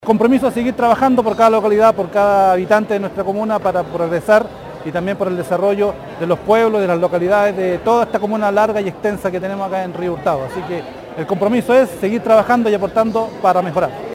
Mientras que el concejal Juan Perines sostuvo que seguirá con su compromiso parea con todas las localidades de Río Hurtado.